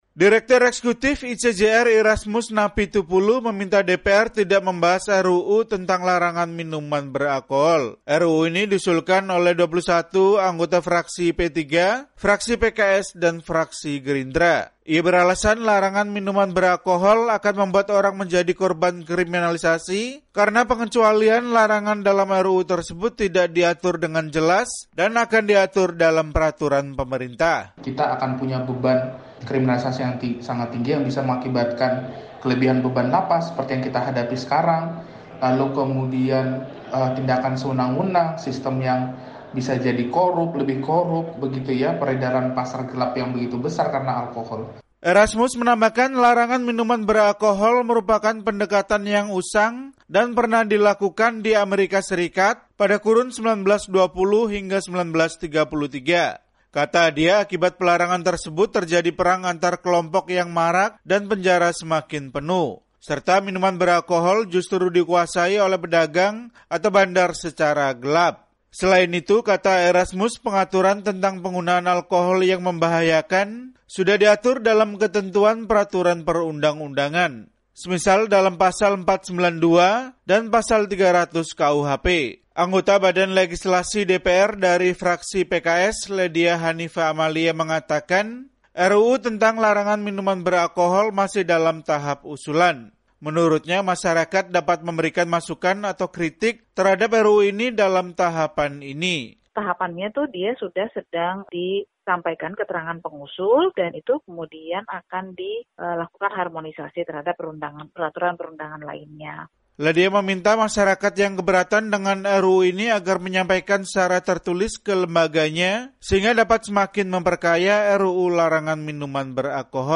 menyampaikan laporannya dari Jakarta.